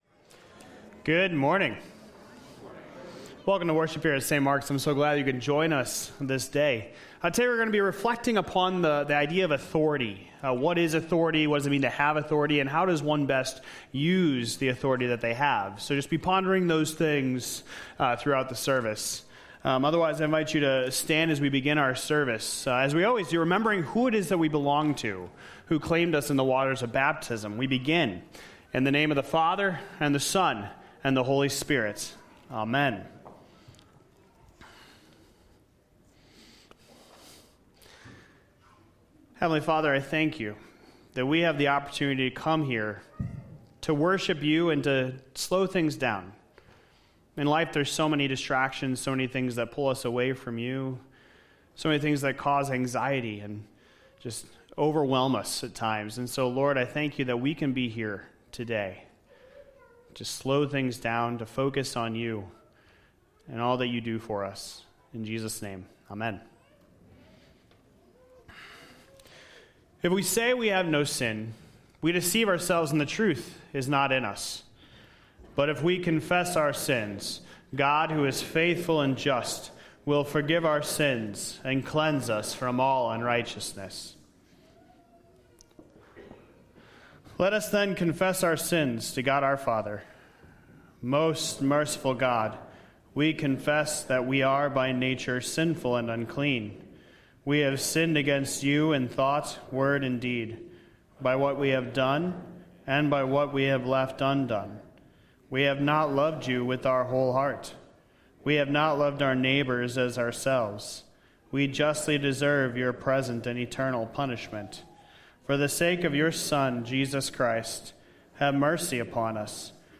All: Amen Opening Prayer Confessing Of Sin Leader: If we say we have no sin, we deceive ourselves, and the truth is not in us.
Sermon Scripture Reading Mark 1:21-28 (Pew Bible pg.1425) [21] They went to Capernaum, and when the Sabbath came, Jesus went into the synagogue and began to teach.